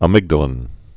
(ə-mĭgdə-lĭn)